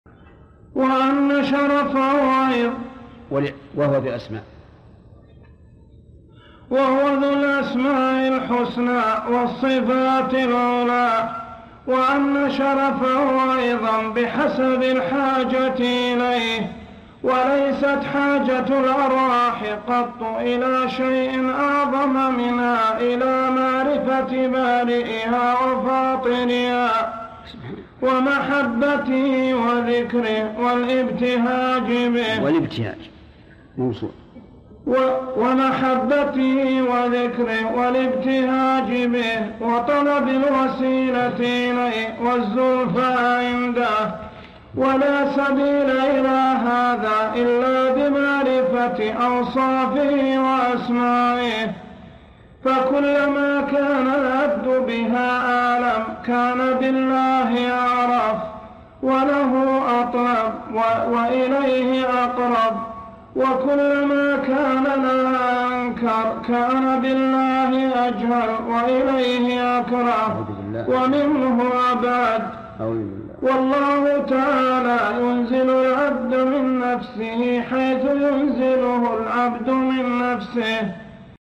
حاجة الأرواح إلى معرفة أسماء الله وصفاته - قراءة من كلام المؤلف - ابن عثيمين